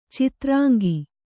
♪ citrāŋgi